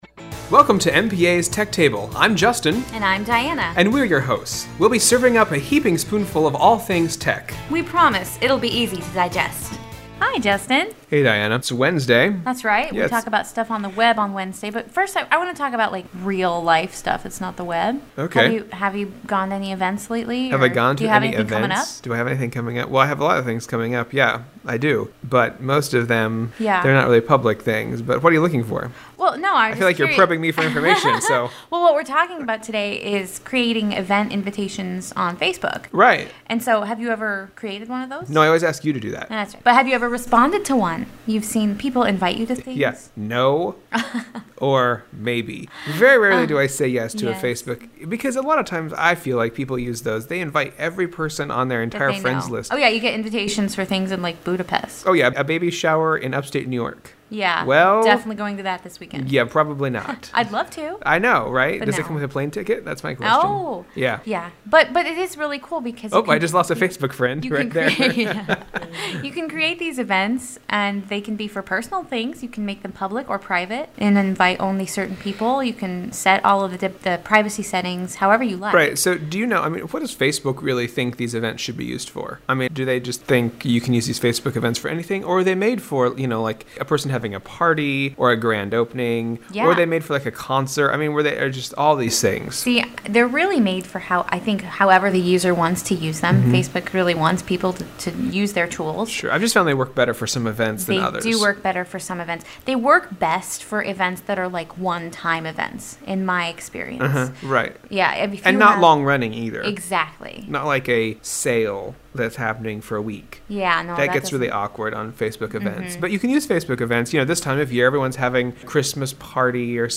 Tech Table Radio Show